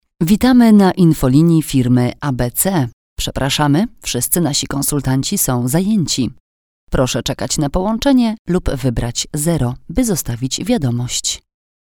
Female 30-50 lat
Experienced voice artist with a warm, round voice timbre that inspires listener trust.